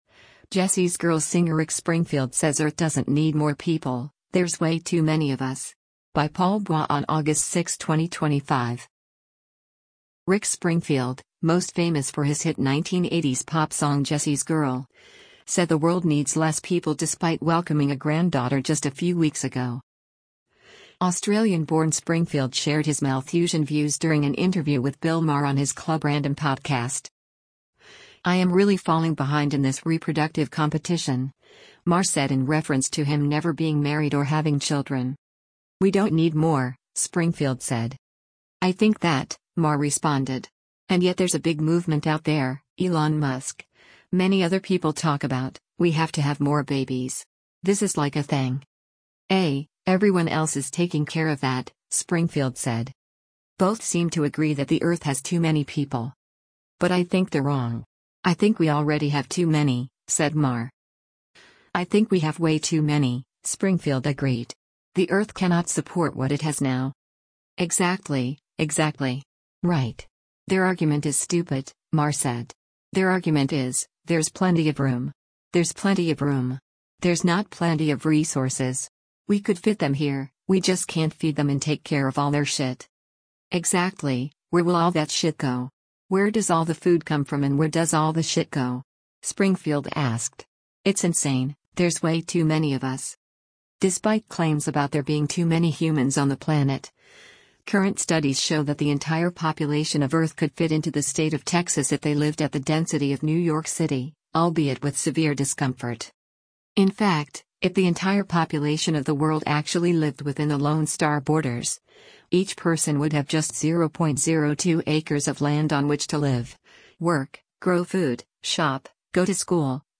Australian-born Springfield shared his Malthusian views during an interview with Bill Maher on his Club Random podcast.